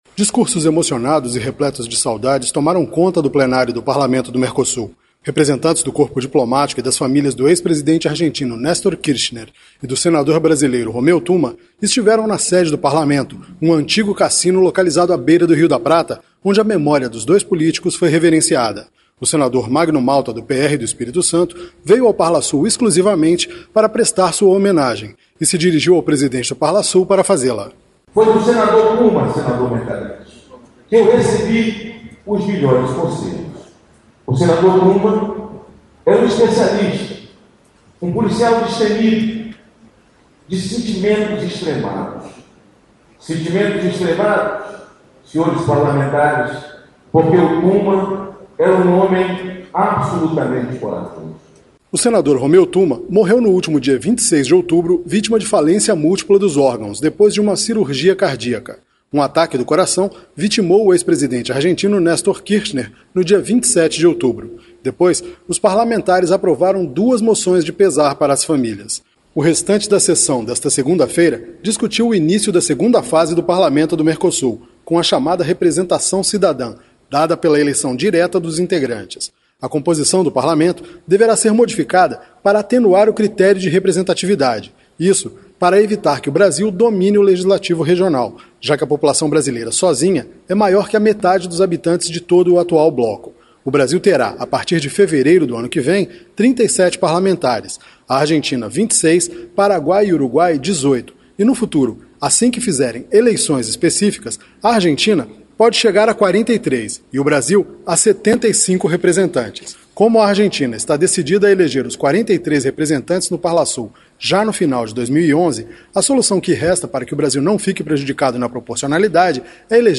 CONFIRA OS DETALHES DIRETO DA CAPITAL URUGUAIA. Discursos emocionados e repletos de saudades tomaram conta do Plenário do Parlamento do Mercosul.